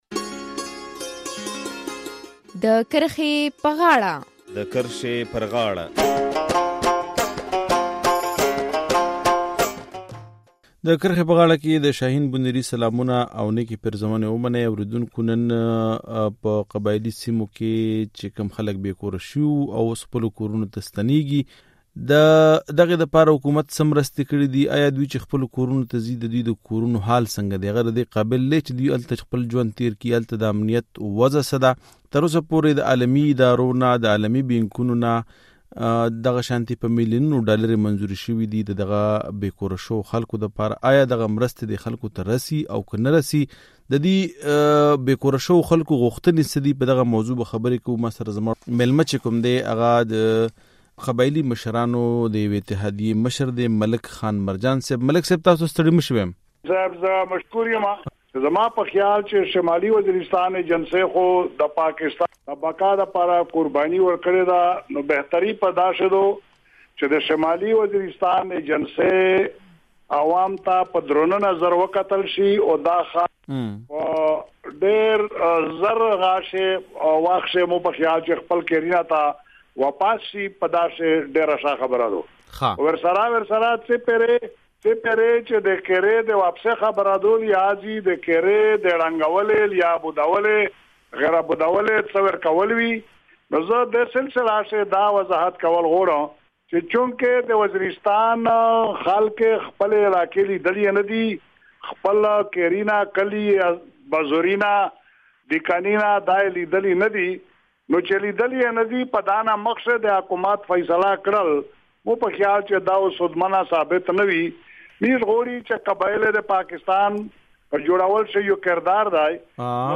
چارواکي وايي چې قبایلي سیمو ته د بېکوره شوي خلکو د ستنېدو عمل پیل شوی دی. خو ځني قبایلي مشران بیا وايي چې تر څو ورته د خپلو تاوانونو پوره معاوضه نه وي ترلاسه شوی کورونو ته نه ستنیږي. د کرښې پر غاړه خپرونه کې د قبایلي مشرانو او له چارواکو سره په دغه موضوع بحث کوو.